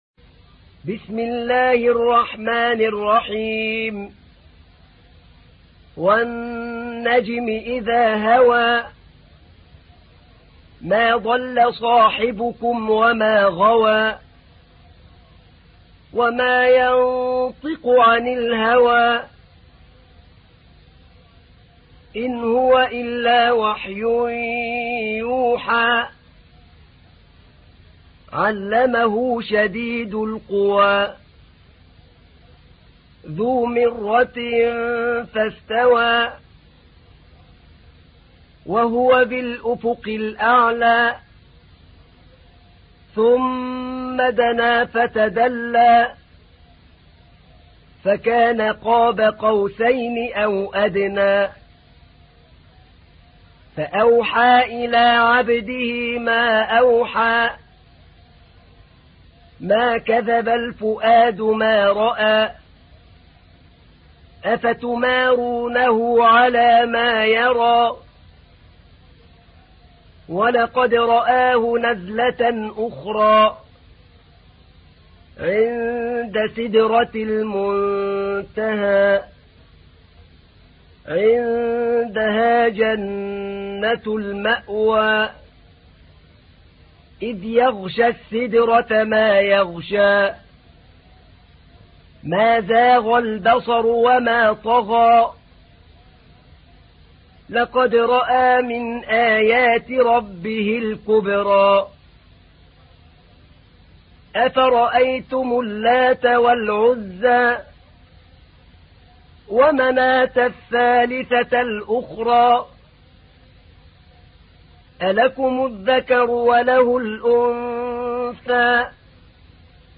تحميل : 53. سورة النجم / القارئ أحمد نعينع / القرآن الكريم / موقع يا حسين